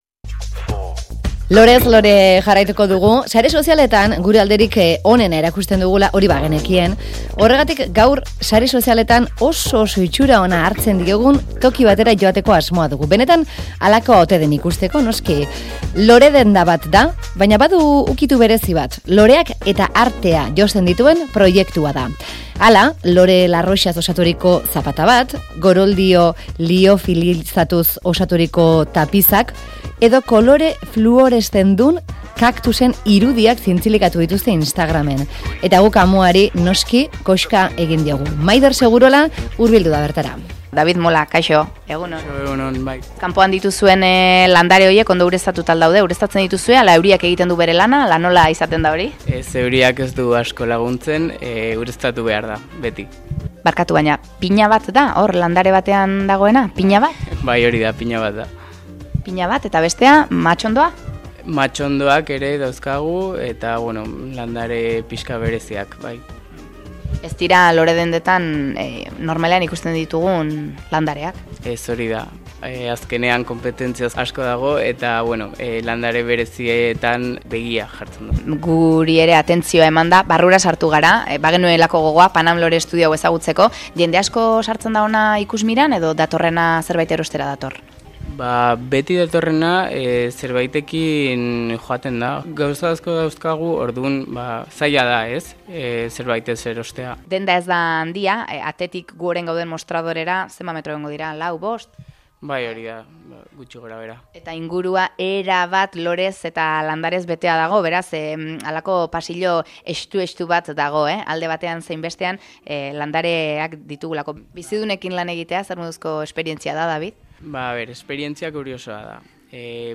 Audioa: Euskadi Irratian Paname Lore Estudioari bisita